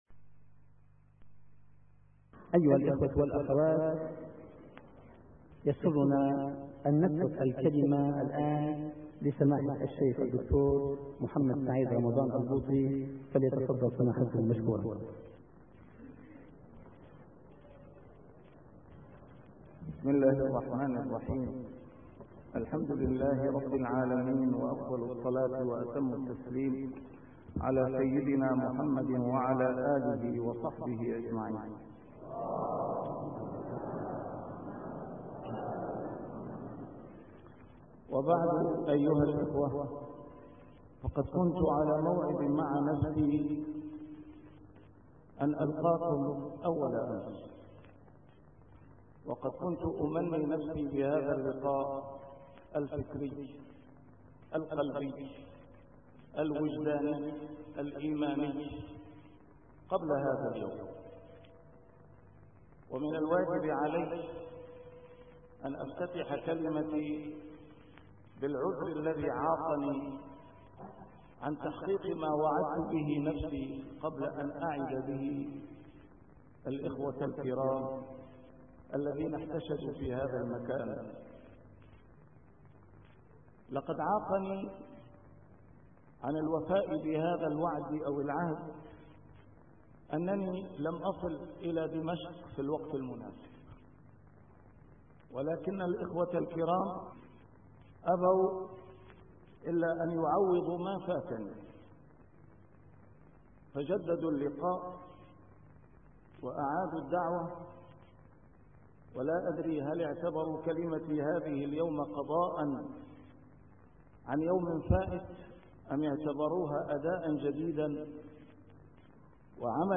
محاضرات متفرقة في مناسبات مختلفة - A MARTYR SCHOLAR: IMAM MUHAMMAD SAEED RAMADAN AL-BOUTI - الدروس العلمية - محاضرة عن عاشوراء